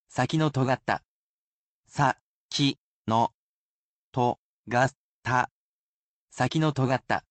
Though he’s a robot, he’s quite skilled at speaking human language. He’s lovely with tones, as well, and he will read each mora so you can spell it properly in kana.